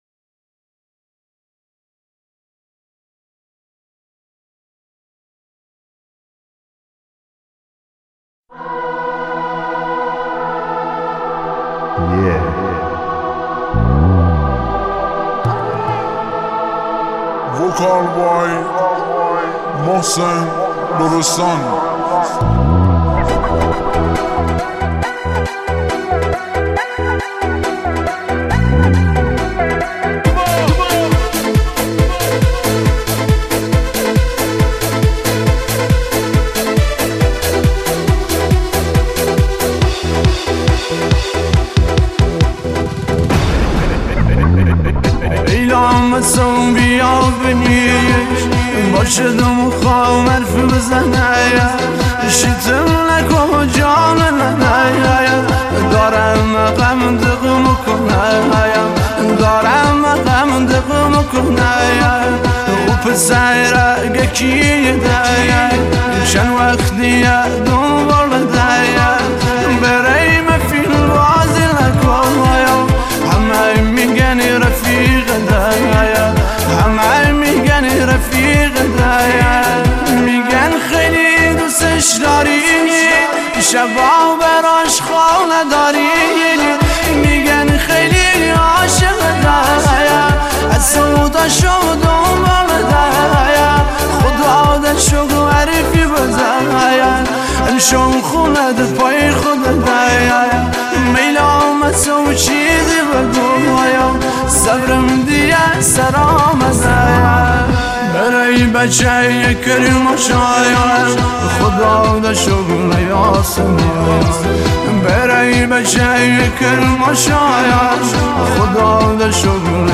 • آهنگ جدید ~ ریمیکس
شاهکار احساسی